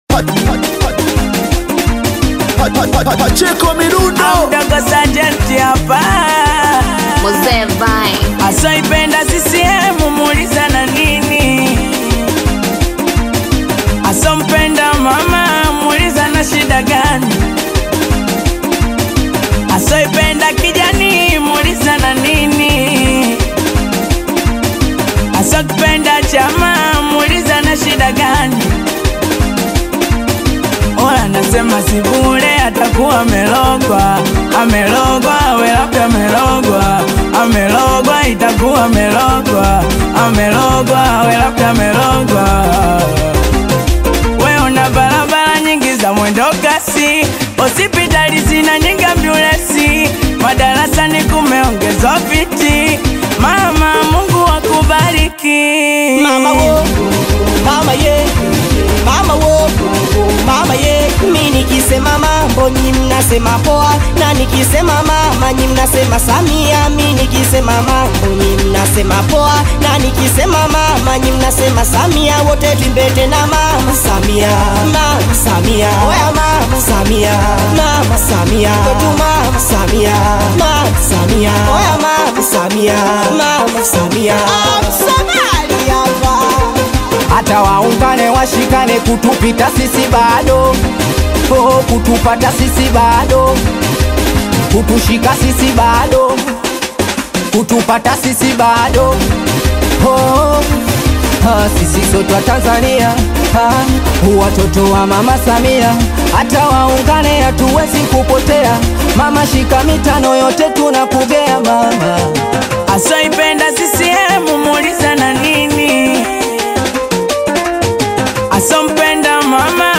Singeli
energetic track
club banger